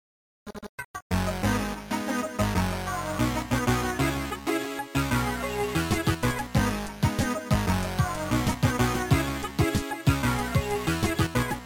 Sound Format: Noisetracker/Protracker
Sound Style: Disco